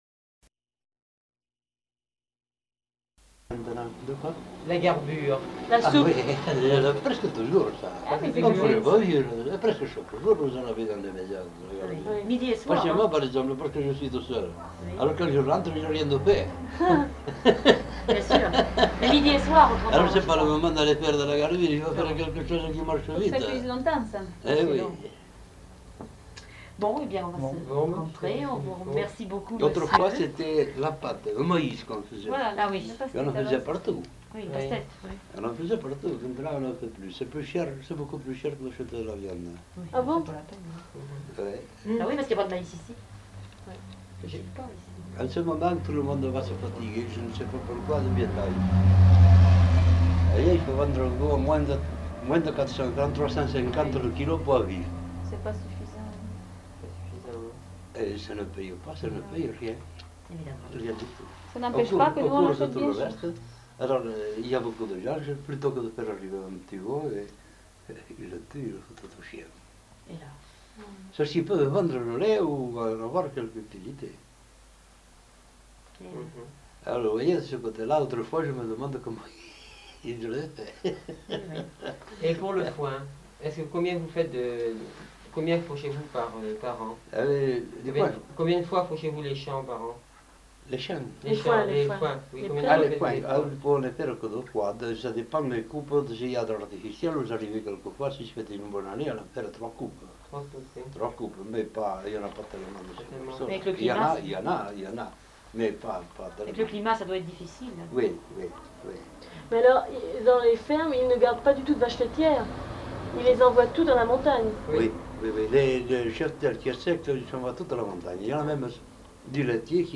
Nature du document : enquête
Type de son : mono
Qualité technique : moyen
Résumé : Enquête courte réalisée dans le cadre du stage Bigorre-Ariège en 1967 auprès d'un homme âgé originaire du Couserans. Il est tout d'abord question de pratiques culinaires telles que la garbure et la pâte de maïs puis des travaux aux champs et de l'élevage des vaches.